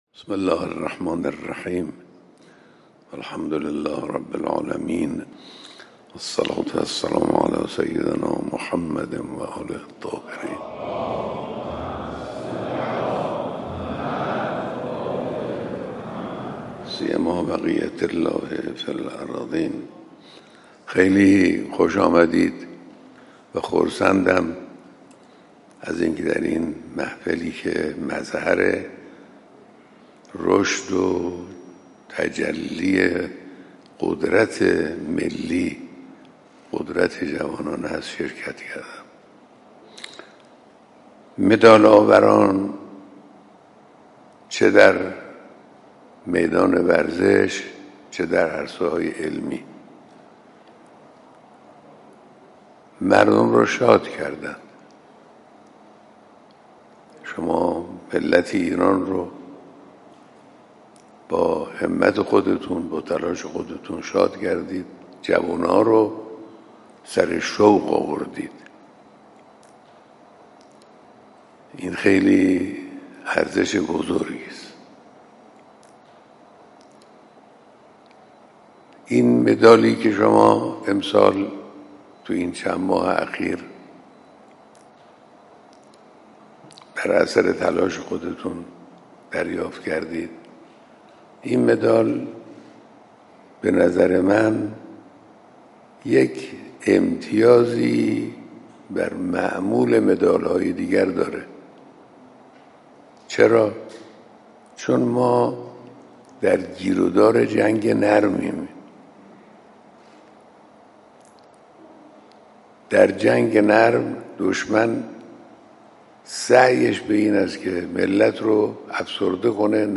صوت کامل بیانات صبح امروز رهبر انقلاب در دیدار قهرمانان و مدال‌آوران ورزشی و المپیادهای علمی جهانی را بشنوید.